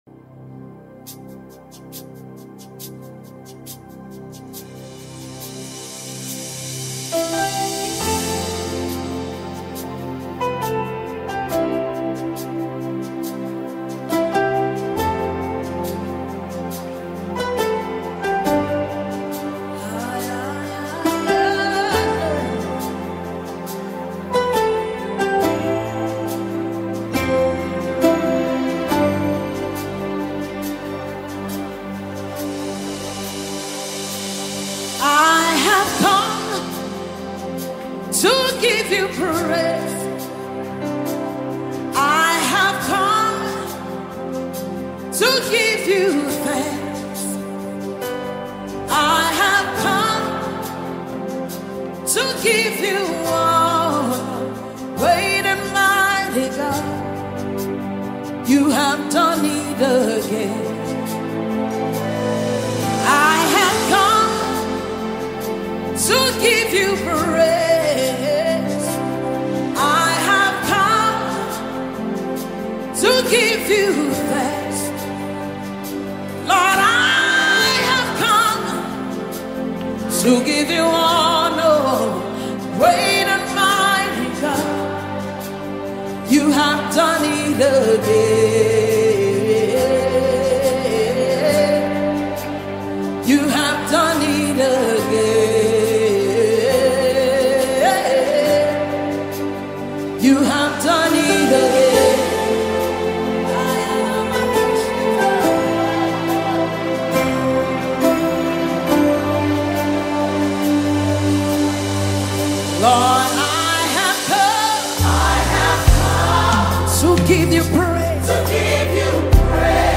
Nigerian Gospel Singer and Worship Leader
exaltation song